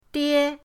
die1.mp3